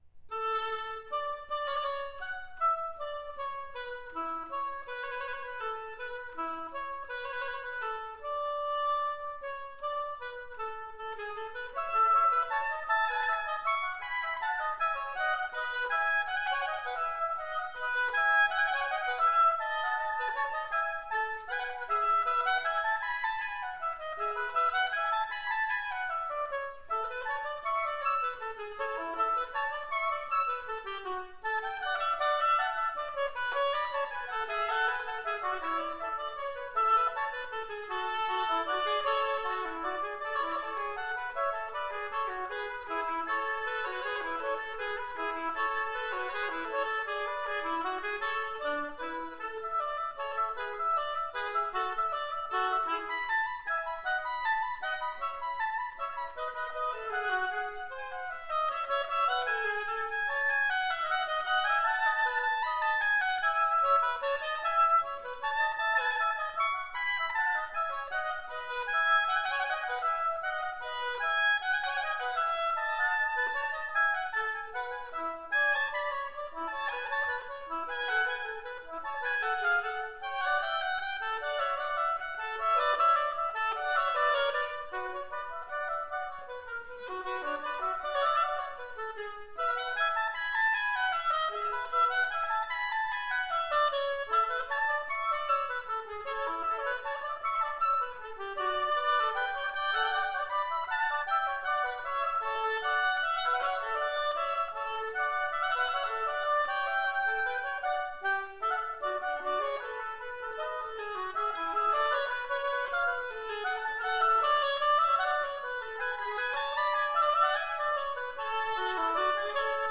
(September 2009)- Tanna Schulich Recital Hall, McGill, Montreal
Mvt 2: Vivace
telemann-vivace.mp3